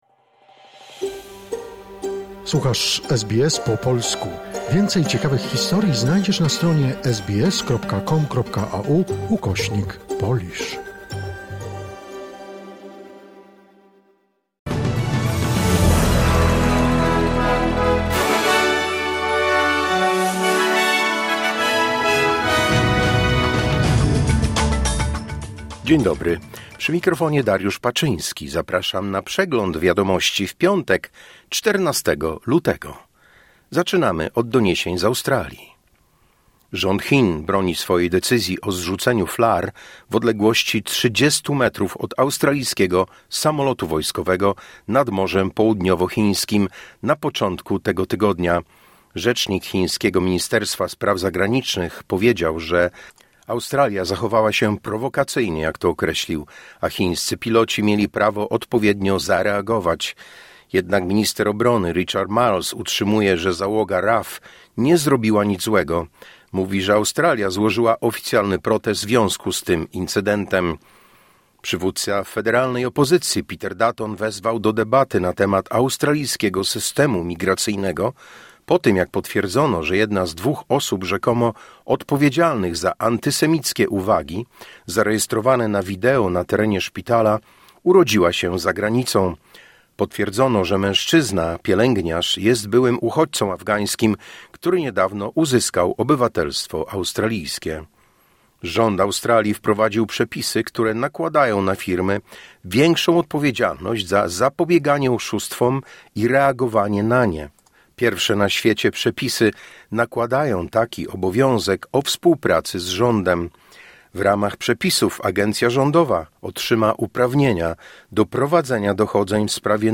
Wiadomości 14 lutego SBS News Flash